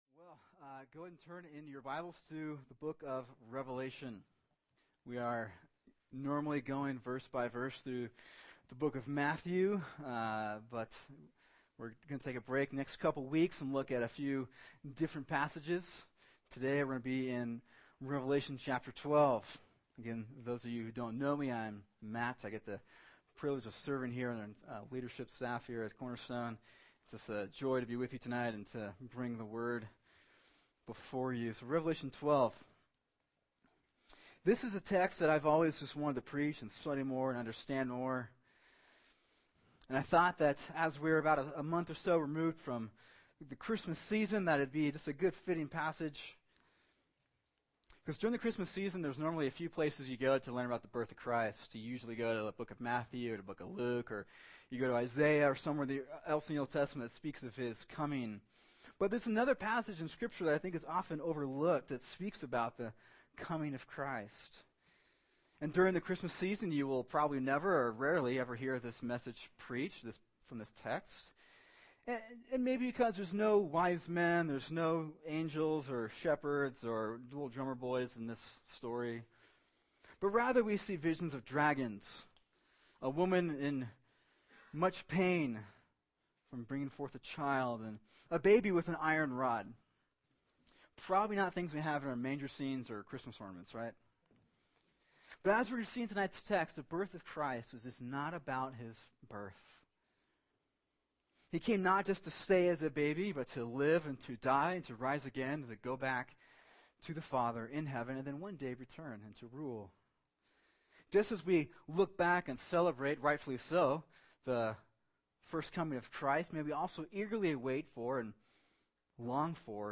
[sermon] Revelation 12:1-6 “The Child Born to Rule” | Cornerstone Church - Jackson Hole